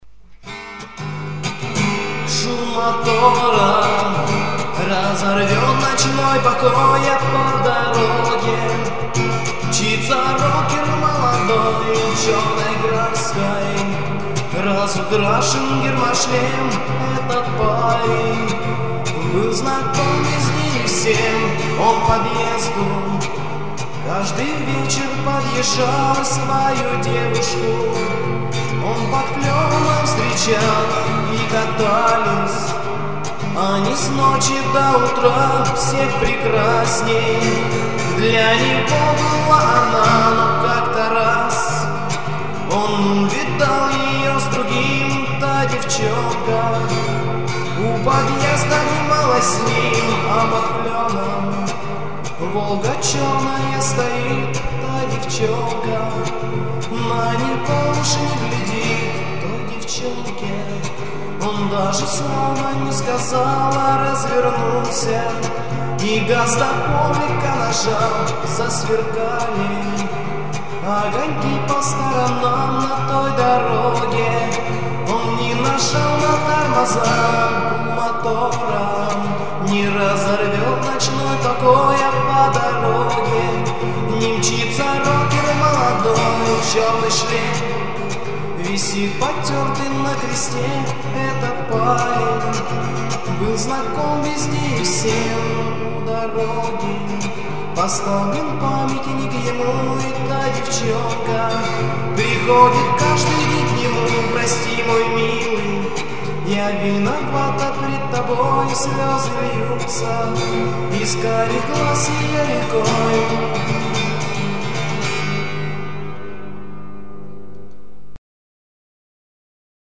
Ключевые слова: грусть, печаль, девчонка, о ней,